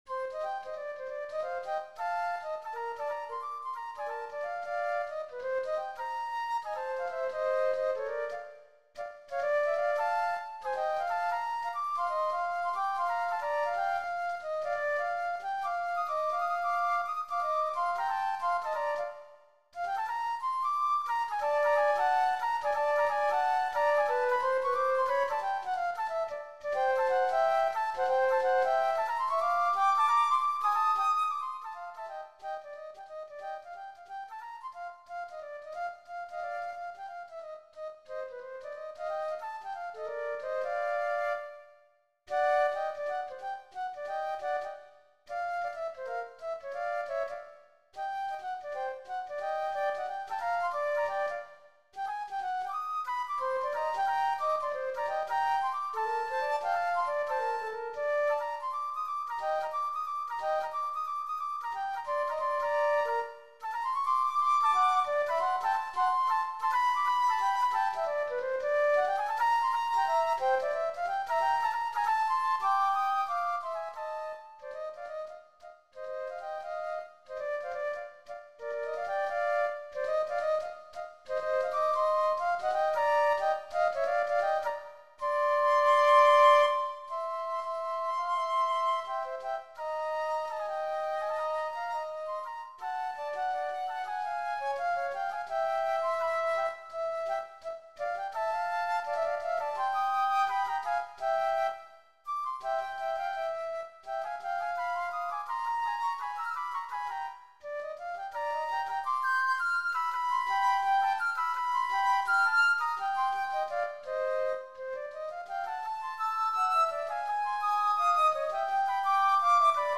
Gattung: Für 2 Flöten